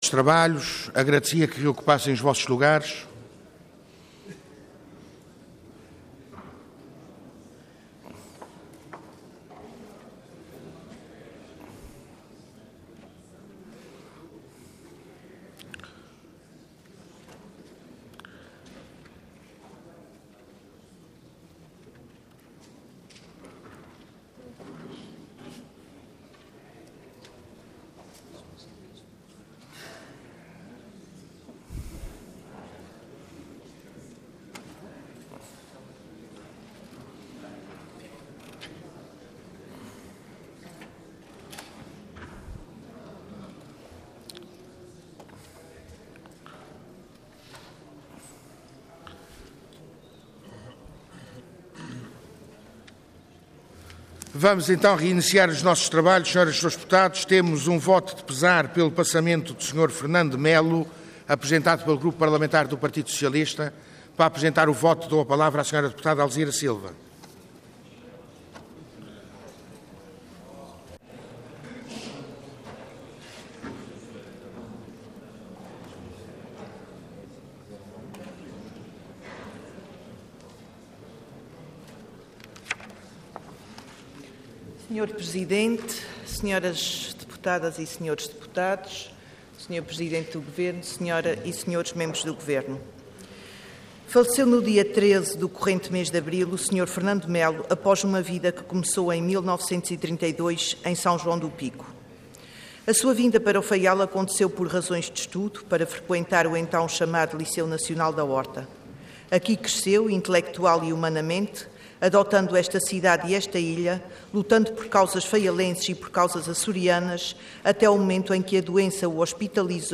Website da Assembleia Legislativa da Região Autónoma dos Açores
Intervenção Voto de Pesar Orador Alzira Silva Cargo Deputada Entidade PS